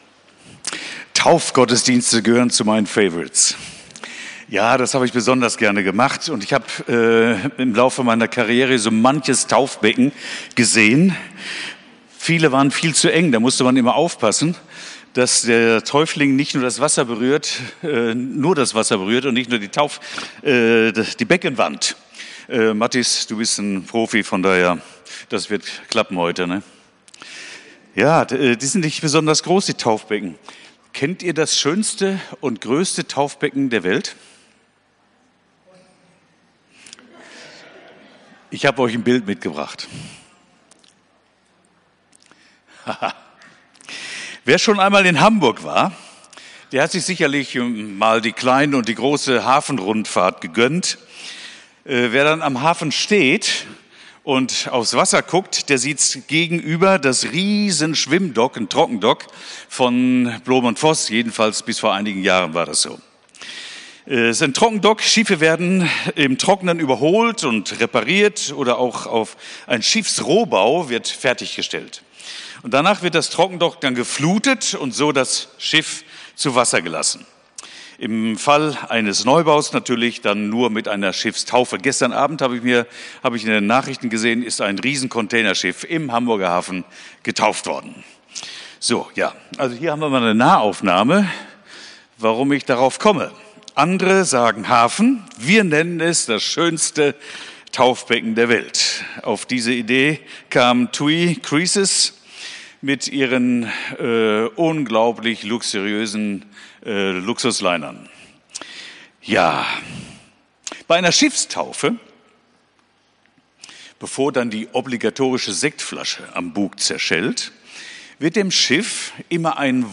Predigt vom 15.06.2025